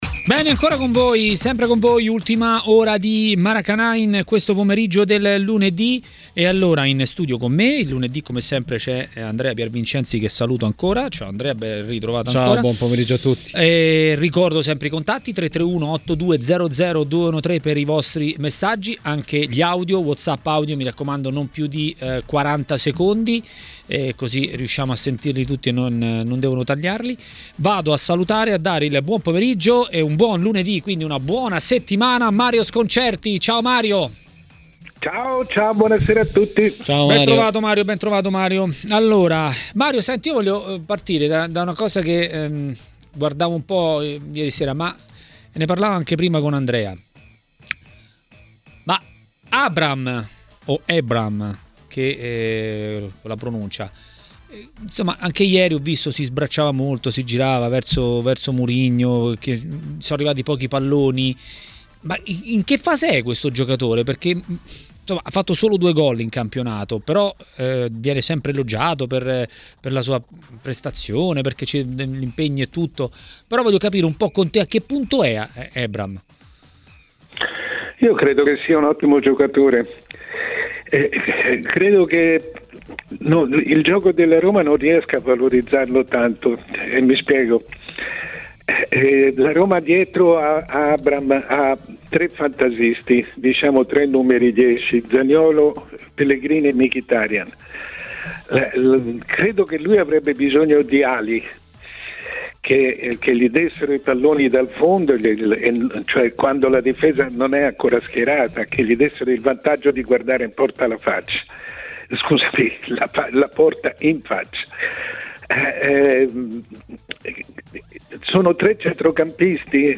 A commentare la Serie A e i suoi temi a TMW Radio, durante Maracanà, è stato il direttore Mario Sconcerti.